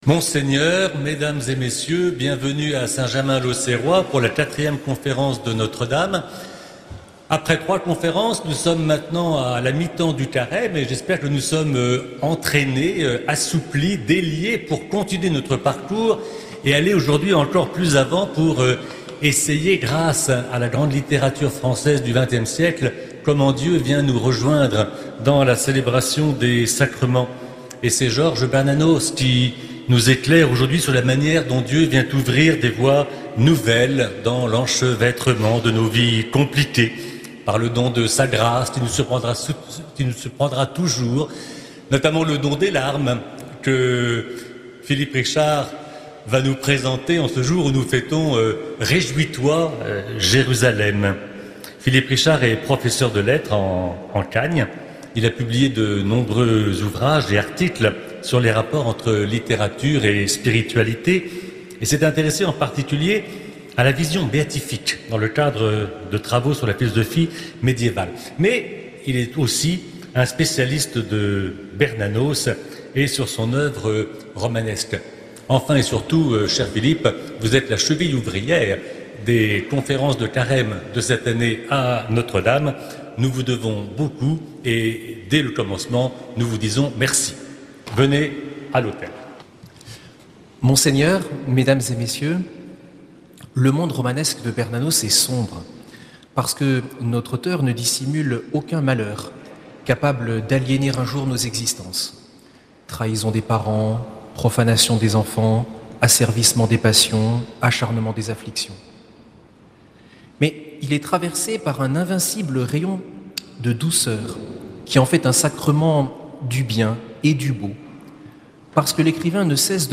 Conférences de carême